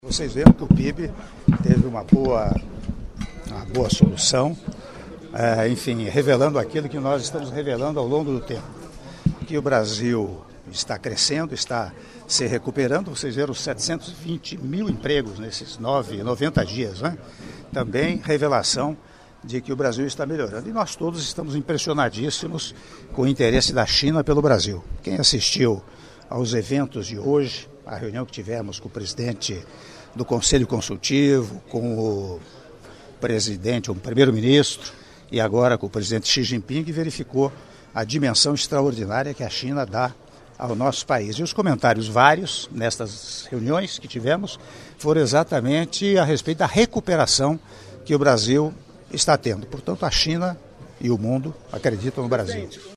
Entrevista coletiva concedida pelo Presidente da República, Michel Temer, na chegada ao Hotel St. Regis - Pequim/China (0min52s)